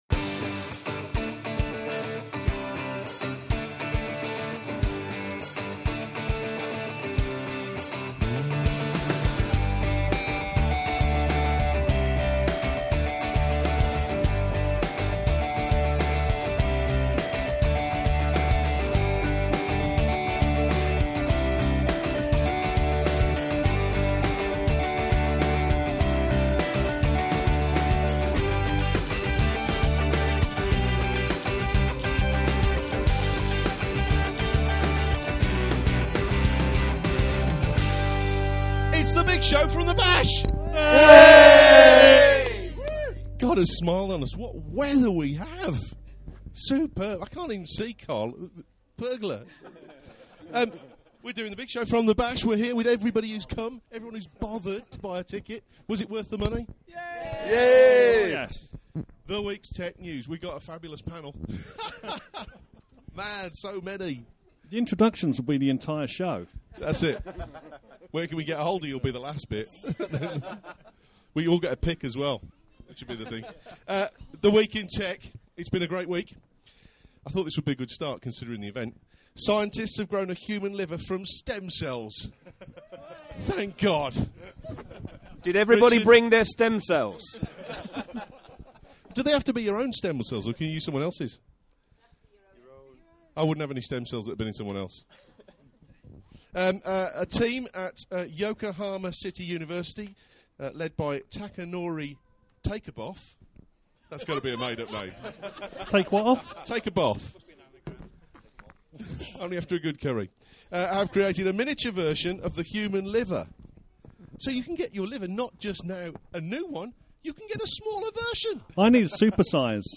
All the fun and games were recorded.